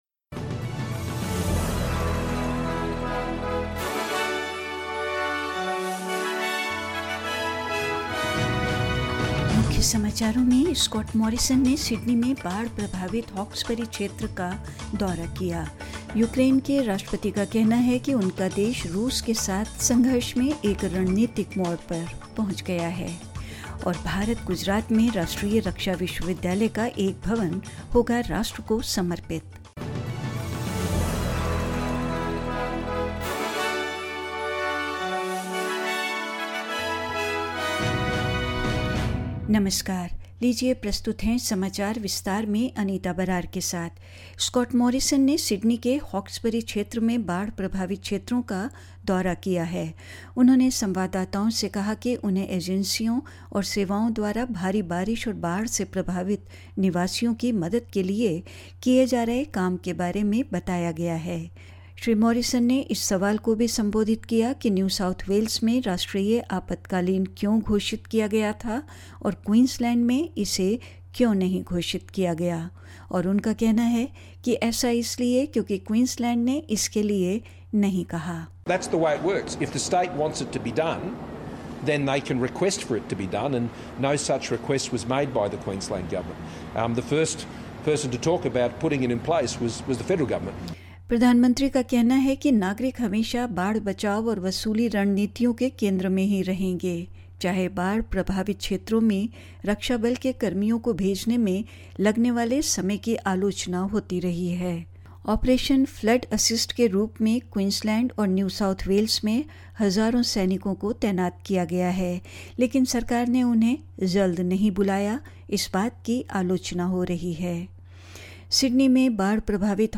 In this latest SBS Hindi bulletin: Scott Morrison tours the flood-affected Hawkesbury region in Sydney; Ukraine's President Volodymyr Zelensky says his country has reached a "strategic turning point"; In India, PM Modi will dedicate the building of Rashtriya Raksha University in Gujarat to the nation and more news.